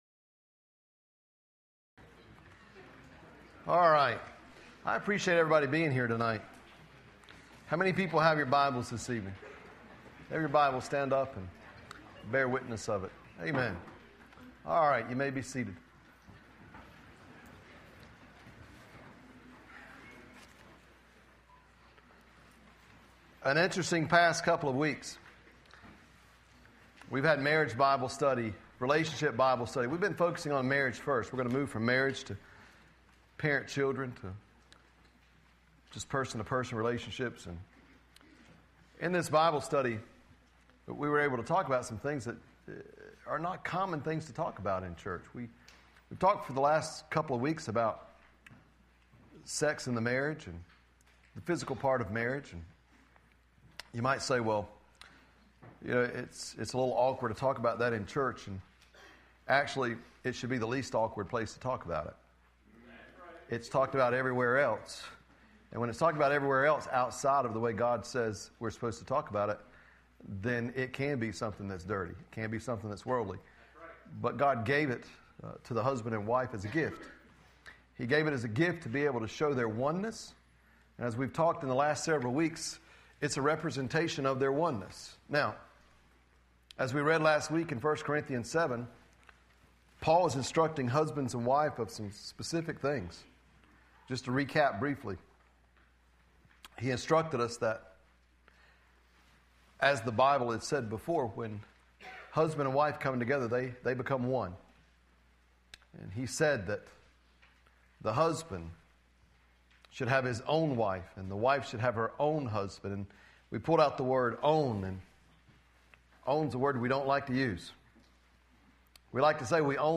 Listen to Sunday Night Bible Studies | Nazareth Community Church